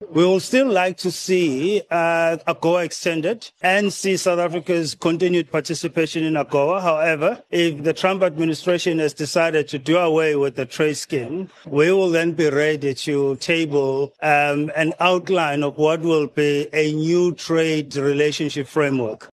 Nuus